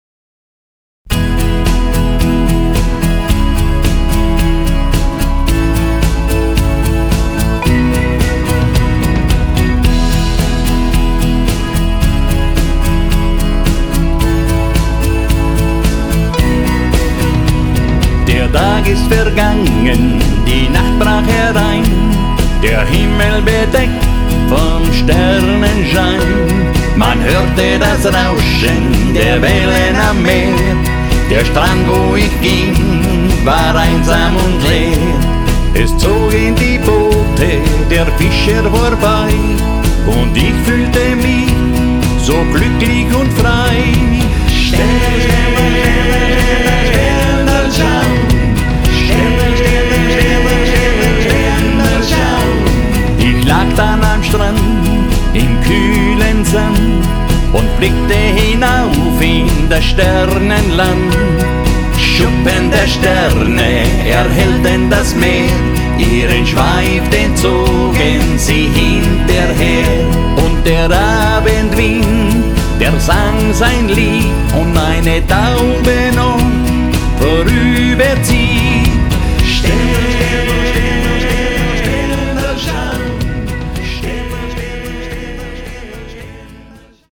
Tanz- und Unterhaltungsmusik